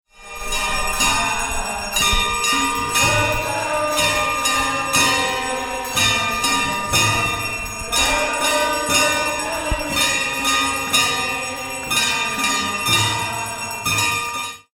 The ghant is a bell that is used during worship. It is rung by devotees as they enter the shrine room and during ceremonies such as arti.